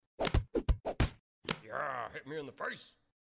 Triple Slap - Botón de Efecto Sonoro